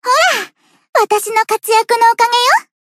贡献 ） 分类:蔚蓝档案语音 协议:Copyright 您不可以覆盖此文件。
BA_V_Koharu_Battle_Move_2.ogg